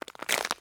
Ice Footstep 9.ogg